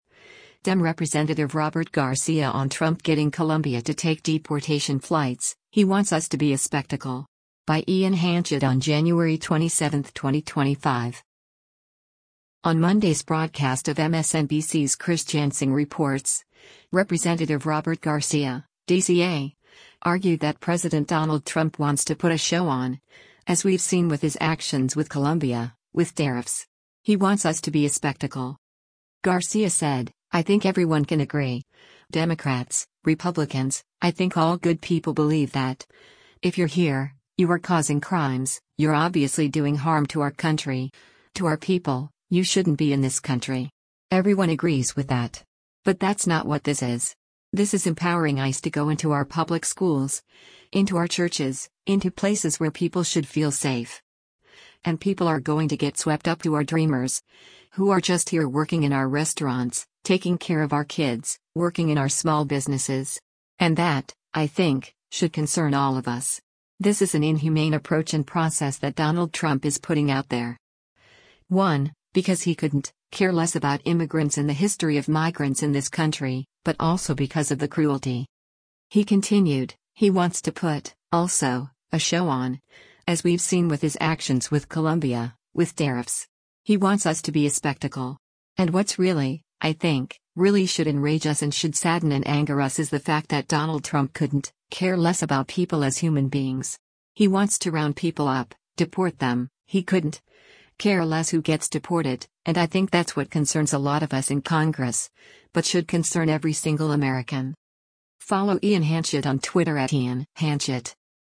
On Monday’s broadcast of MSNBC’s “Chris Jansing Reports,” Rep. Robert Garcia (D-CA) argued that President Donald Trump wants to put “a show on, as we’ve seen with his actions with Colombia, with tariffs. He wants us to be a spectacle.”